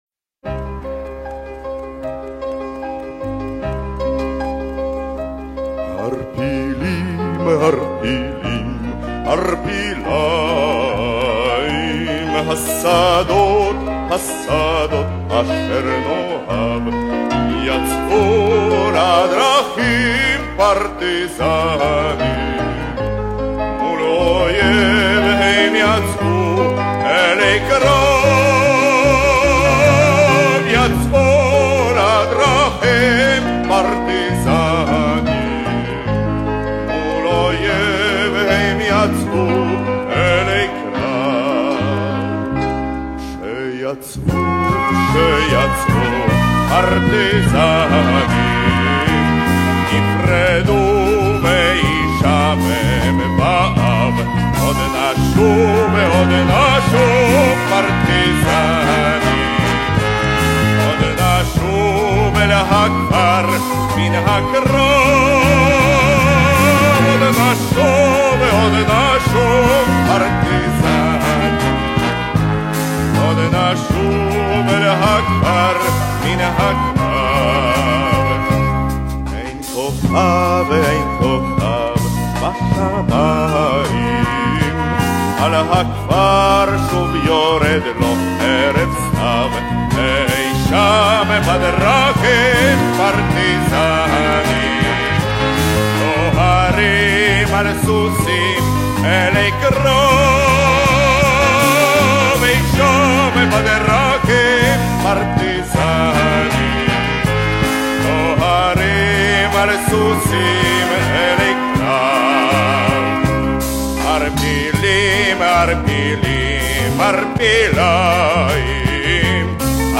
Запись с помехами.